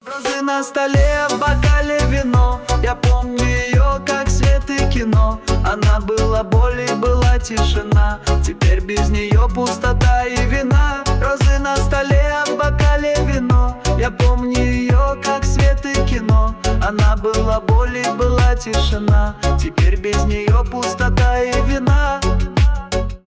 шансон
поп